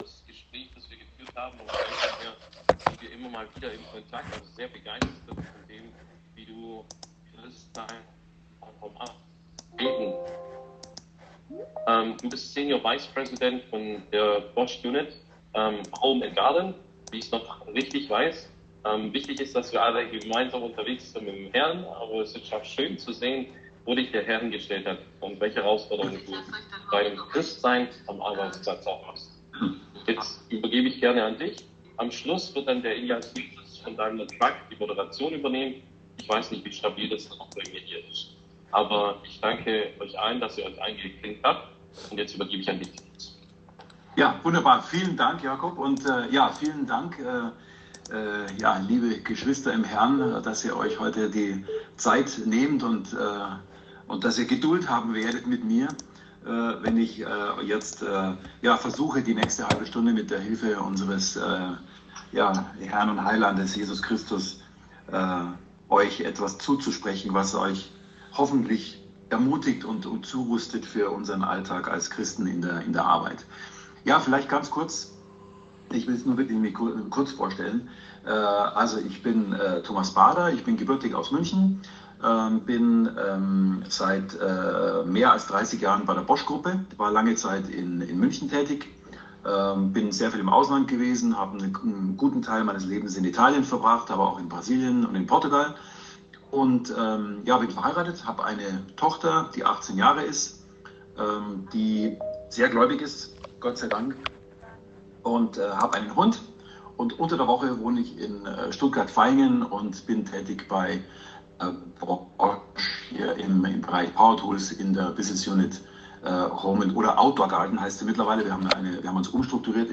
Wir bitten die schlechte Tonqulität zu entschuldigen.